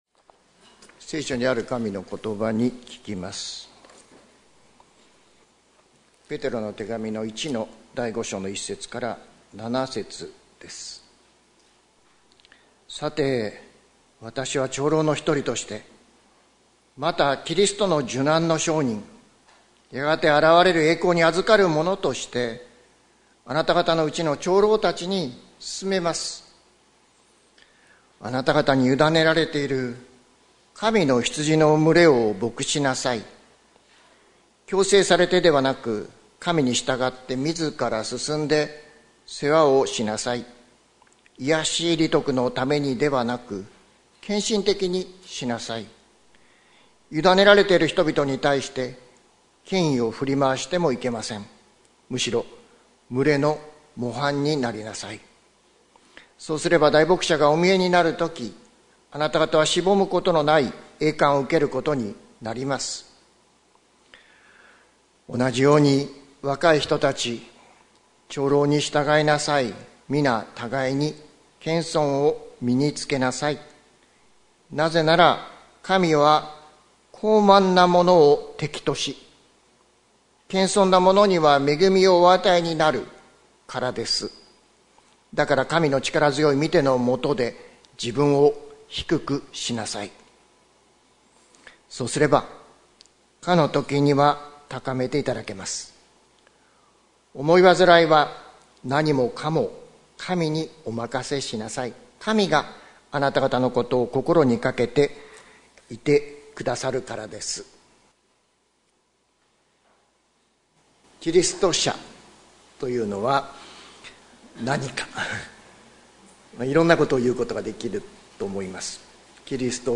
2025年02月16日朝の礼拝「神の力強い御手の下で」関キリスト教会
説教アーカイブ。